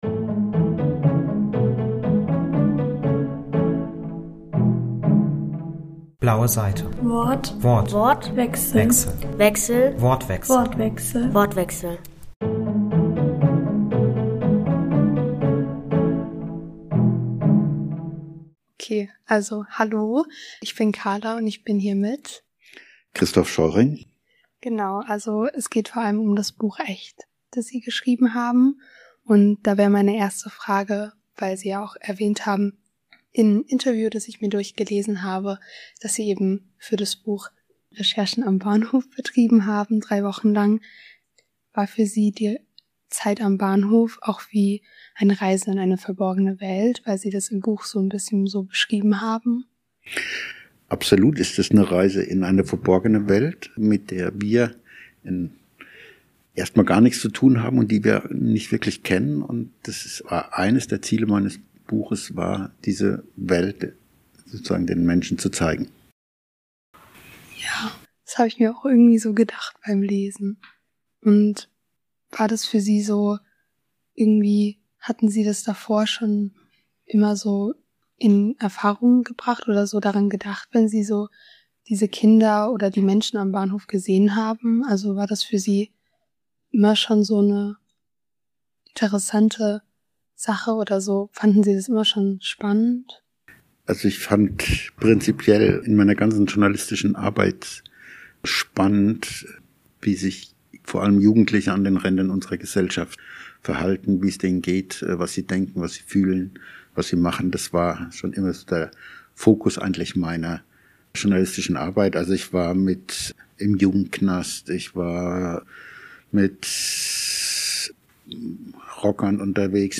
im Rahmen 20. Lübecker Jugendbuchtage über seinen Jugend-Roman "Echt". Er erzählt von seinen Lieblingsbaum sowie von Begegnungen und Bewegungen und davon, wie wichtig es ist jungen Menschen zu zuhören und ihnen eine Stimme zu verleihen.